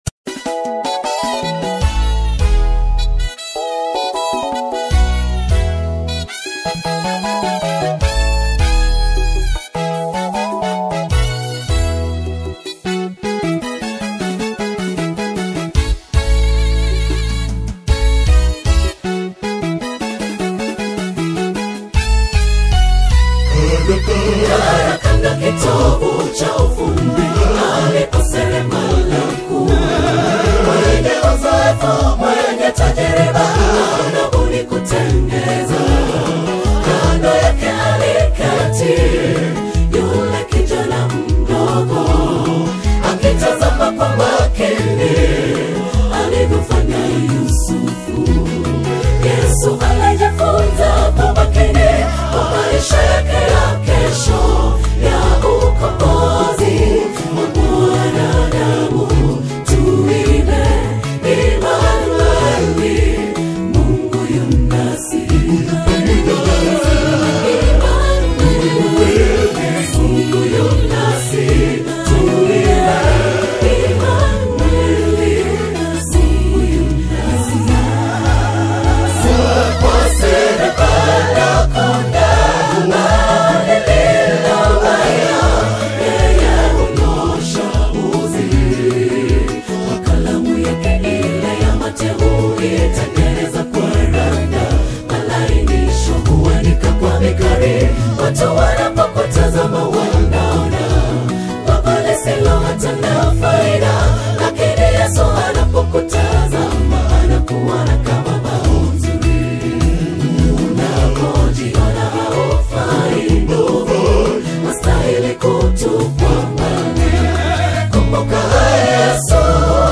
choral
signature harmonic precision and rich vocal textures
Swahili gospel